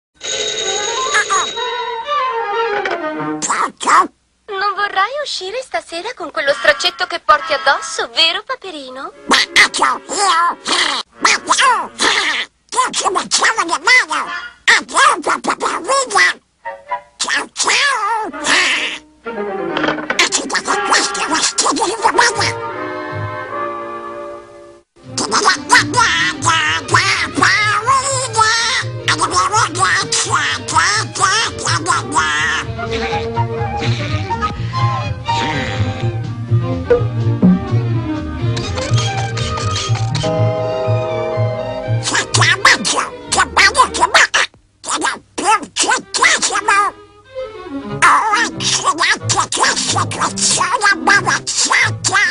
dal mediometraggio "Il weekend di Paperino", in cui doppia Paperino.